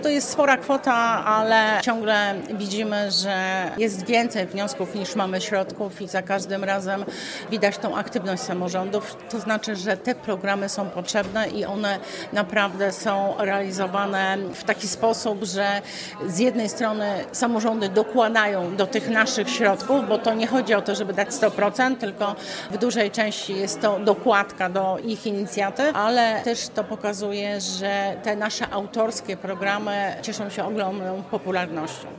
Łączna kwota, jaką przewidzieliśmy dla projektów strażackich i przebudowy dróg w powiecie ostrołęckim to ponad 2,5 mln złotych – mówi Janina Ewa Orzełowska – członek zarządu województwa mazowieckiego: